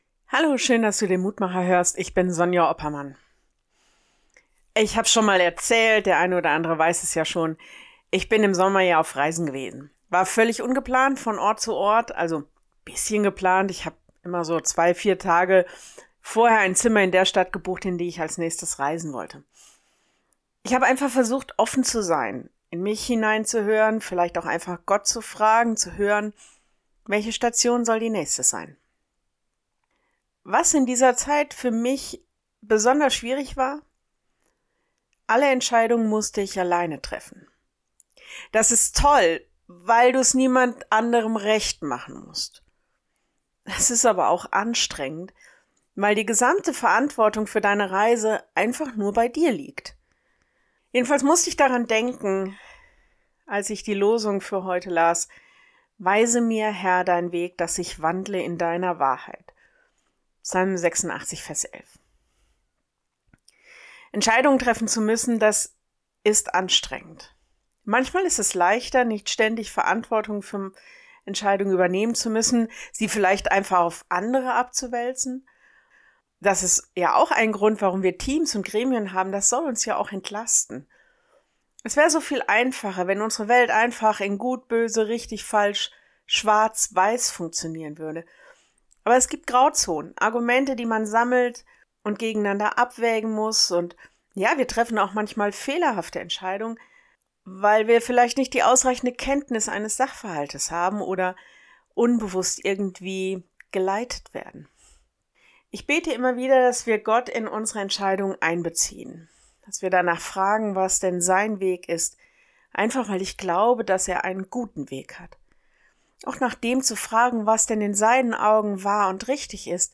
Mit dem Mutmacher hört ihr einen kleinen Impuls und Gedanken zu Losung oder Lehrtext nach den Herrnhuter Losungen. Hört Euch die Andacht an, sprecht mit uns ein Gebet für den Tag.
Die Impulse dauern alle unter 3 Minuten.